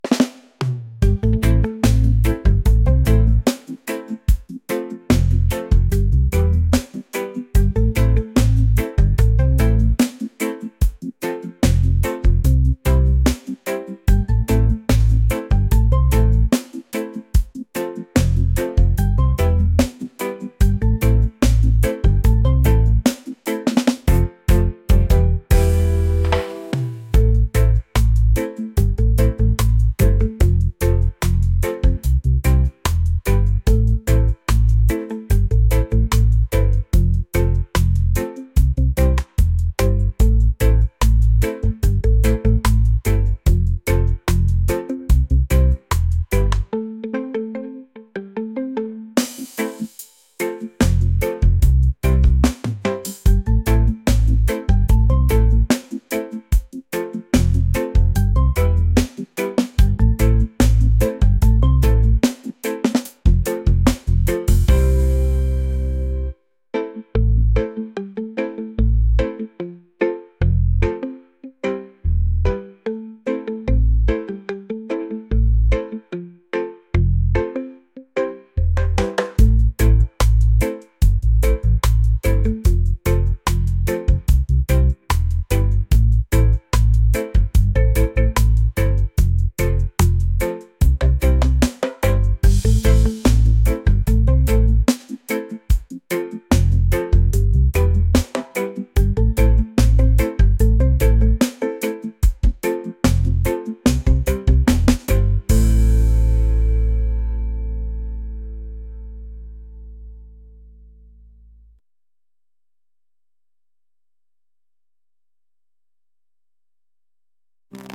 laid-back | reggae | positive | vibes